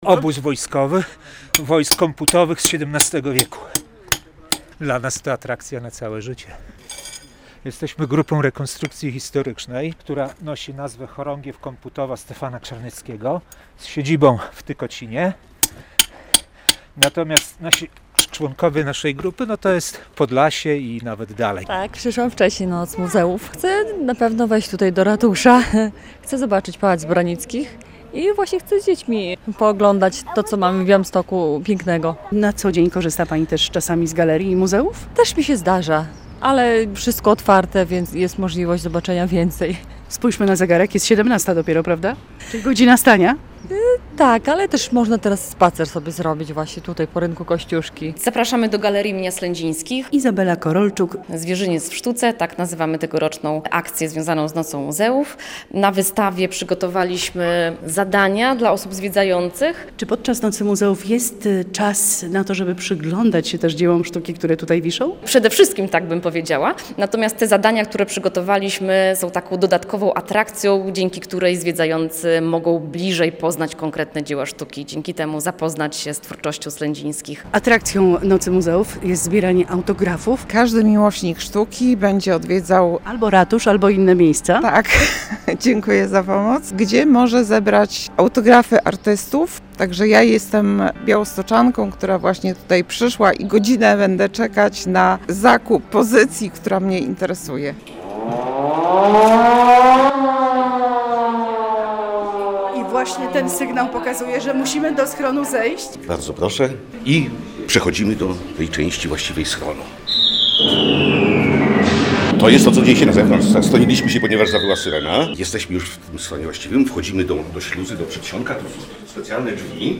Atrakcje na białostockiej Nocy Muzeów - relacja